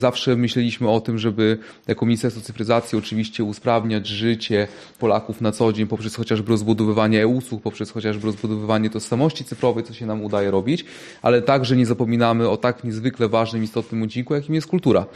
Swoim działaniem chcemy poprawiać ludziom komfort życia podsumowuje minister Adam Andruszkiewicz: